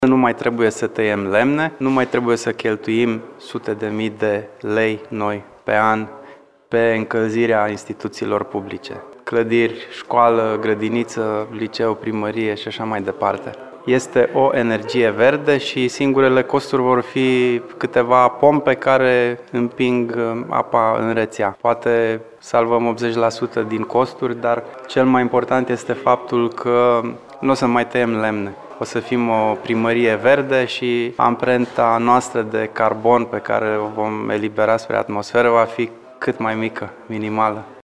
Pe lângă reducerea semnificativa a costurilor cu utilitățile, care se ridica la cateva sute de mii de lei, în perioadele reci, cel mai mare beneficiu este reducerea amprentei de carbon, spune primarul Bono Cucalan.